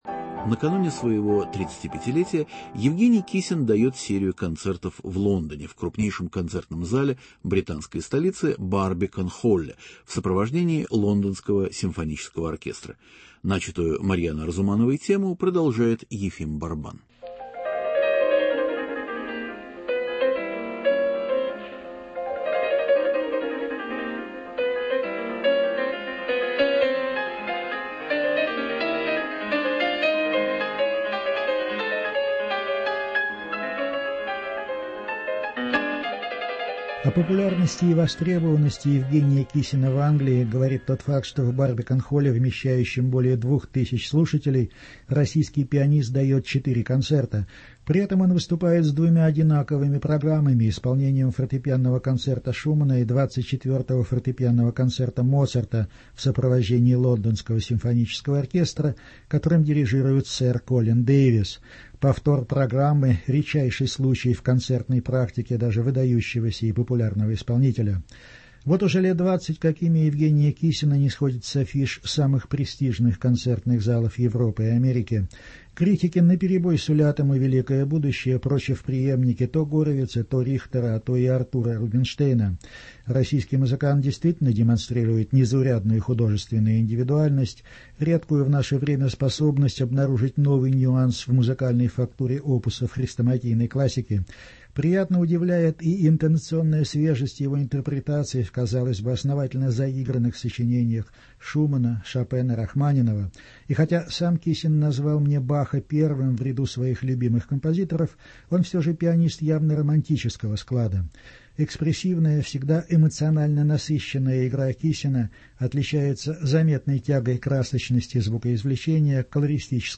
Евгений Киссин о себе и музыке (интервью в Лондоне).